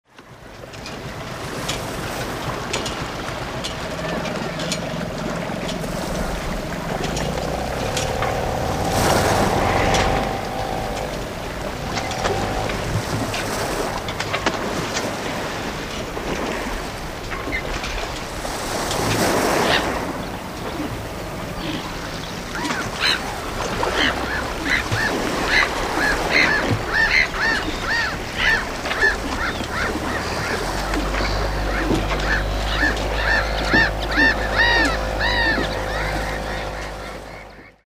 North Sea Relaxation - sound of the sea, piano, crushing waves, seagulls, sounds to calm down and relax - audiobook mp3 download
Audio Auszug / Vorschau North Sea atmosphere pure: waves, seagulls, wind 13:12 min